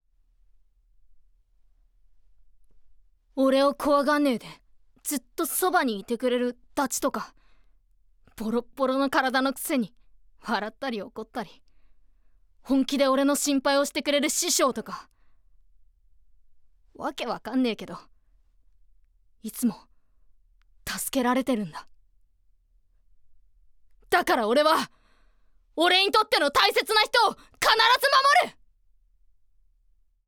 ボイスサンプル
まっすぐな少年